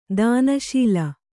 ♪ dāna śila